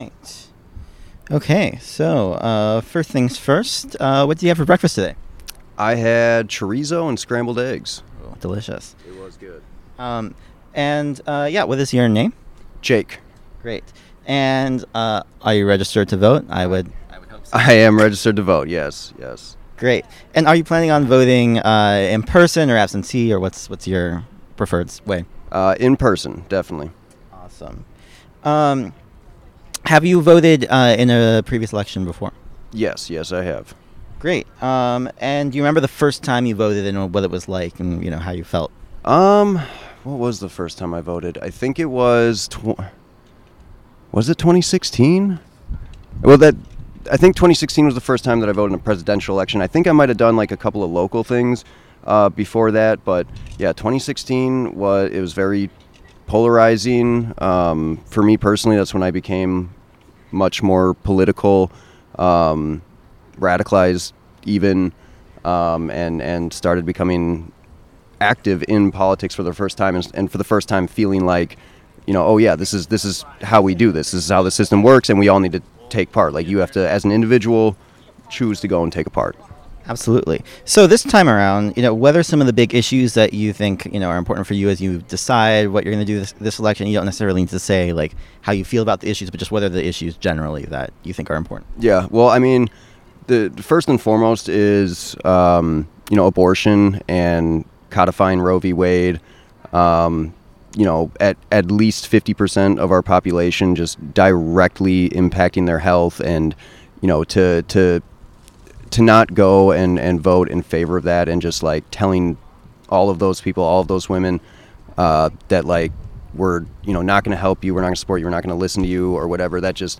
Wisco Soundoff Day 1